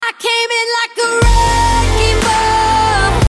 wrekingball.wav